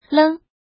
怎么读
léng
leng1.mp3